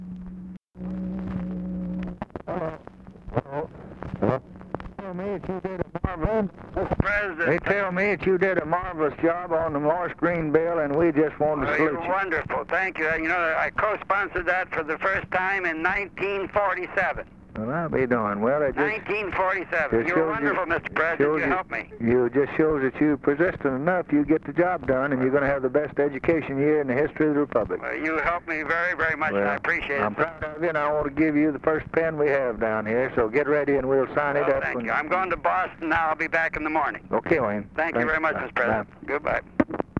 Conversation with WAYNE MORSE, December 10, 1963
Secret White House Tapes